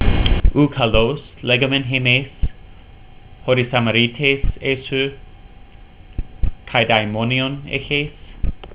You can click on the verse to hear me read it.